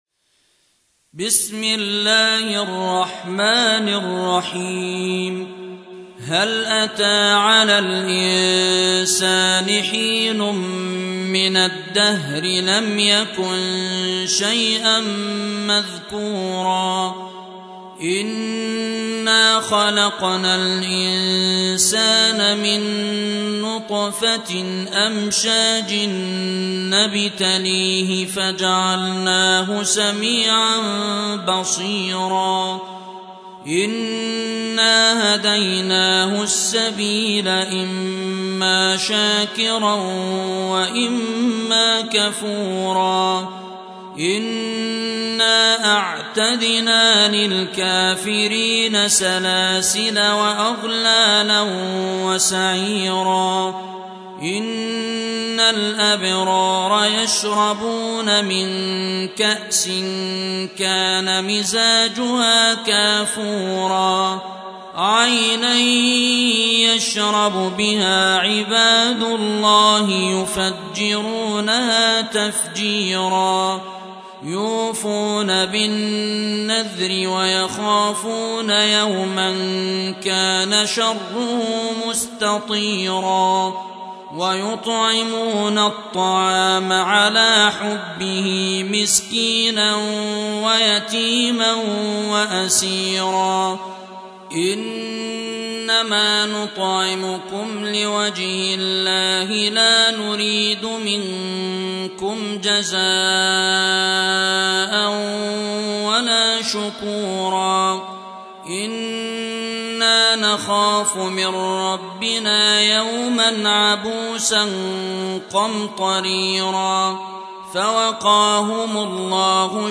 Surah Repeating تكرار السورة Download Surah حمّل السورة Reciting Murattalah Audio for 76. Surah Al-Ins�n or Ad-Dahr سورة الإنسان N.B *Surah Includes Al-Basmalah Reciters Sequents تتابع التلاوات Reciters Repeats تكرار التلاوات